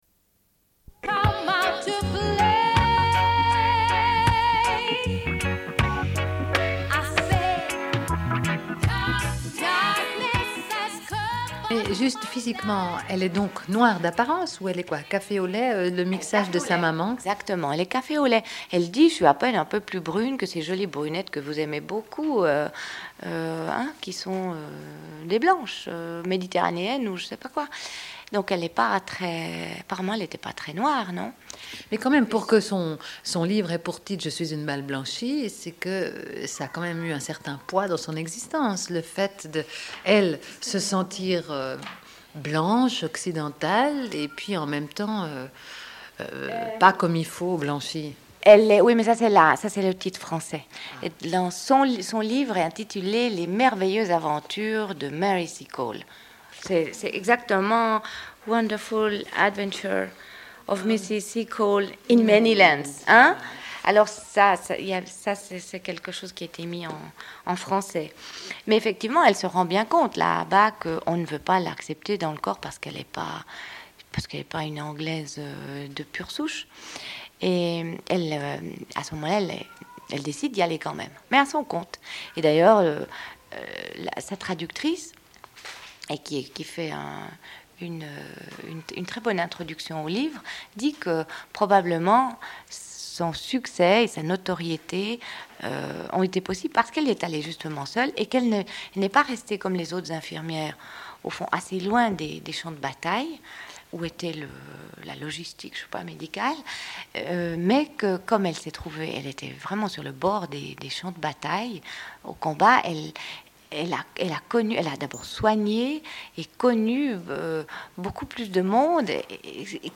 Une cassette audio, face B29:07